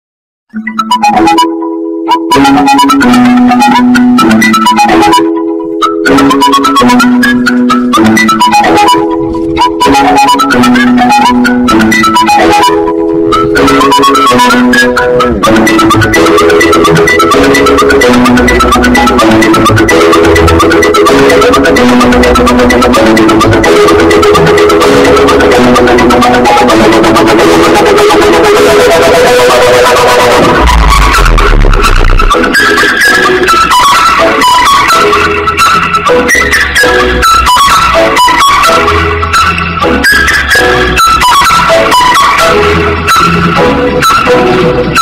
GOOFY MUSIC